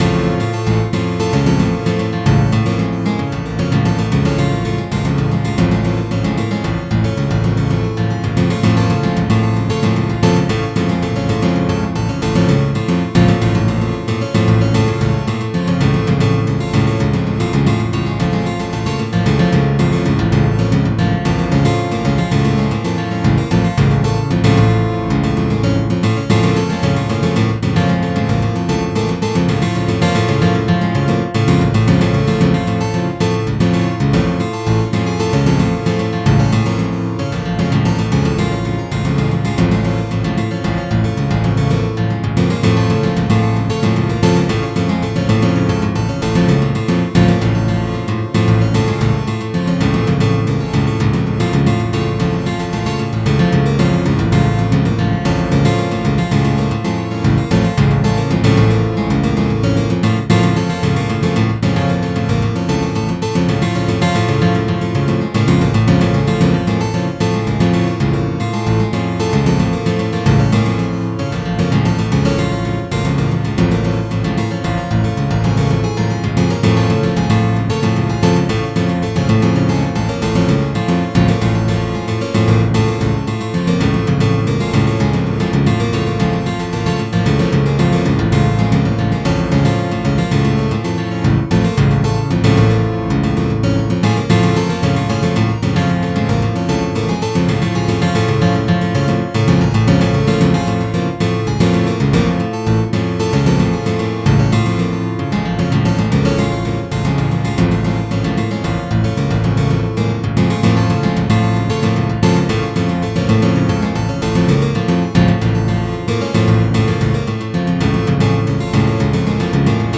エレクトリック・アコースティック・ギターを使用しての
即興的タッピング演奏の仮想ライブ。
with both hands tapping technique
this is the multi-layered simulated guitars album.